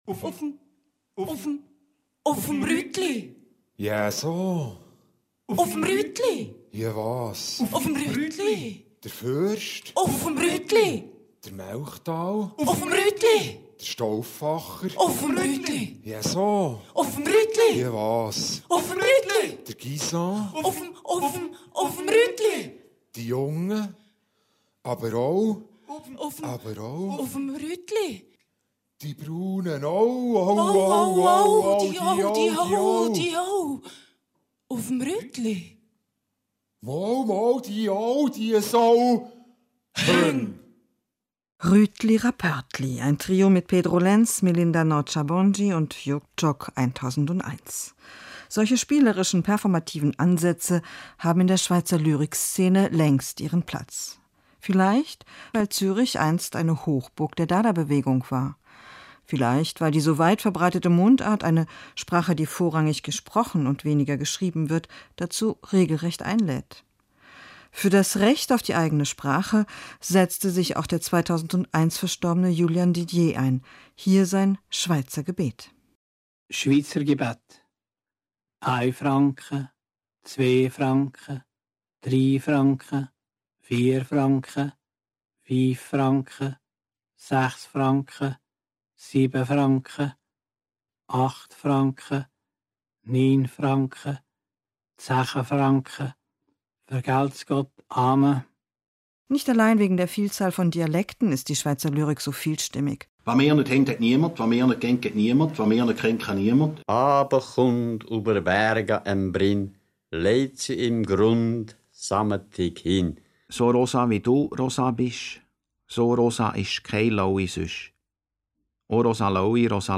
Sie heißt „Wenn ich Schweiz sage“ (Lesung) und lief am 31.8.2013 um 17.30 bei Deutschlandradio Kultur …